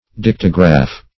dictograph - definition of dictograph - synonyms, pronunciation, spelling from Free Dictionary
Dictograph \Dic"to*graph\ (d[i^]k"t[-o]*gr[.a]f), n. [L. dictum